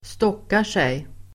Ladda ner uttalet
Uttal: [²ståk:ar_sej]